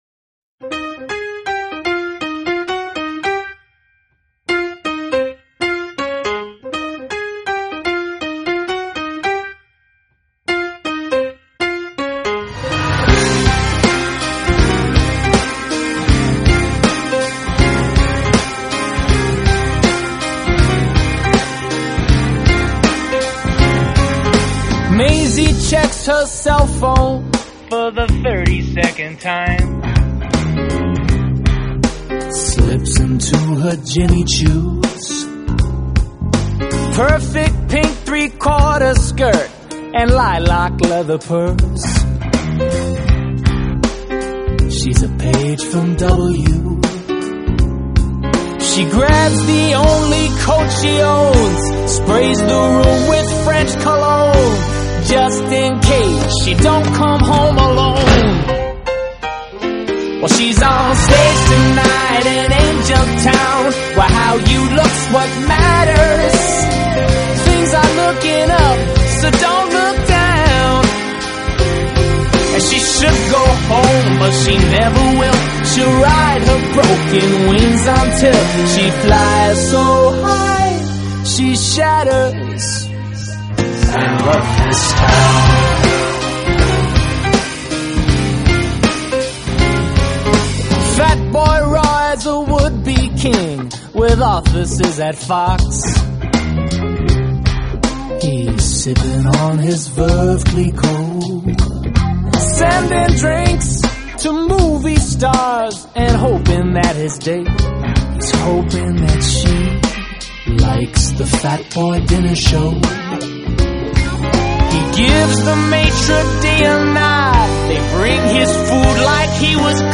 爵士专辑
在这张专辑当中，他的曲 风也变得更加多元化，时而流行，时而摇滚，但其中总少不了他的这份爵士情怀。